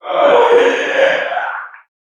NPC_Creatures_Vocalisations_Puppet#37 (search_02).wav